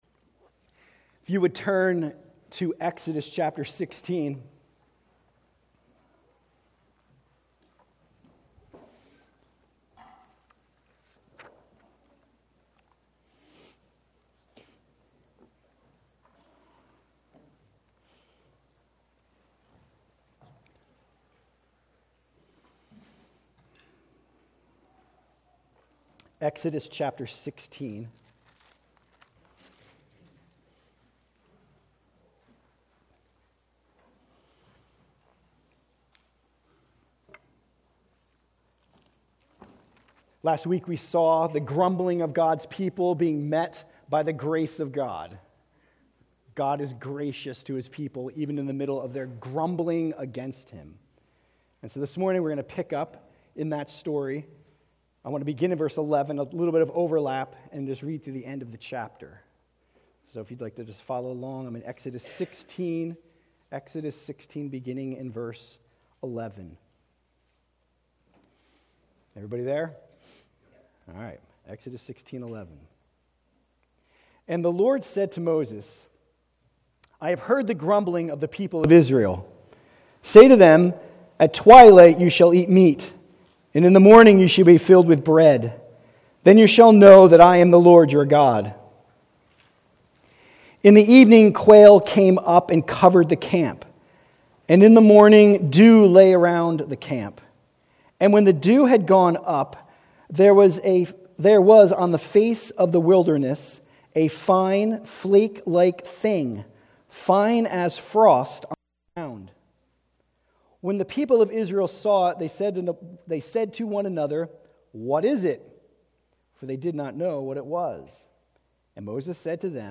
Christ Church of Mt Airy Sermons